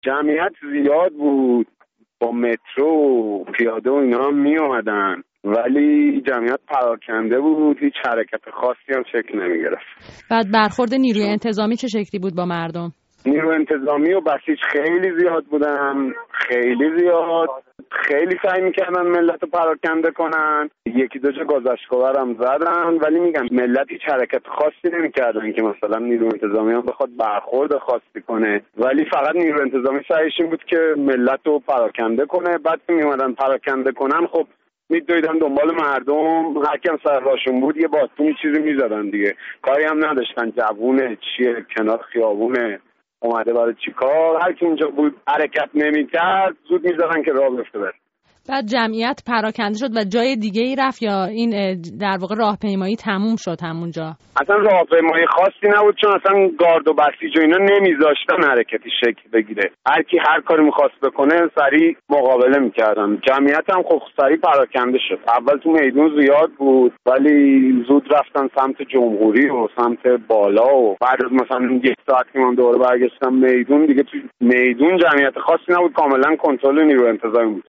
گزارش شاهدان عینی از درگیری های میدان بهارستان - بخش اول